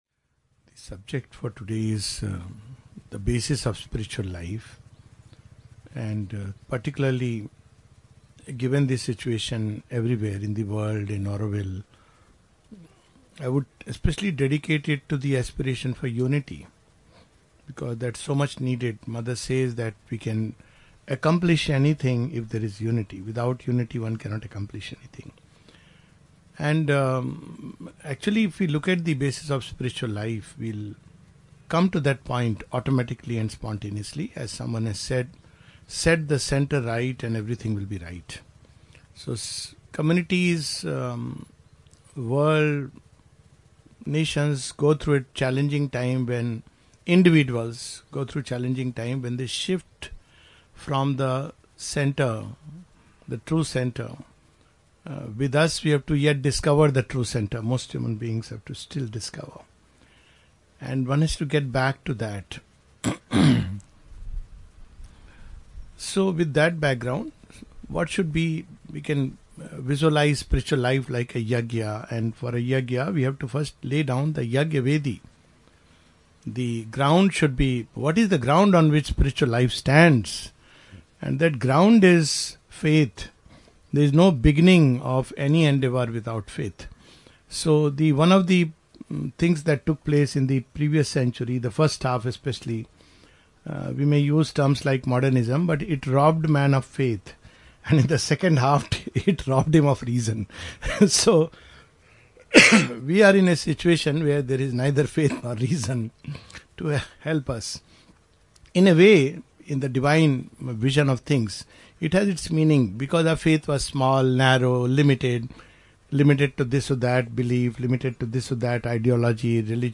A talk
at the Mother's Flower Garden, Auroville, in March 2025.